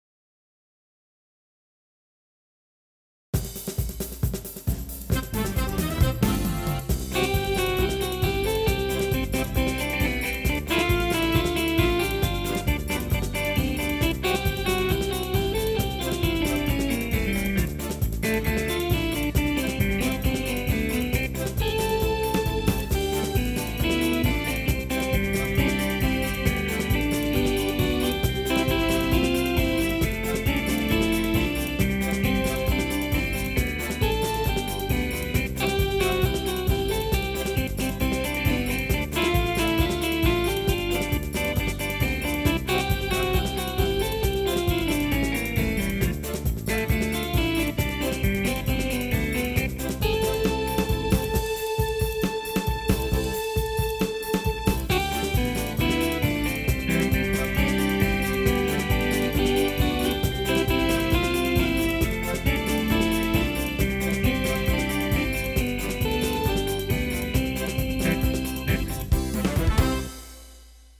As músicas foram executadas com os seguintes teclados:
MÚSICAS EXECUTADAS COM O TECLADO YAMAHA PSR-SX700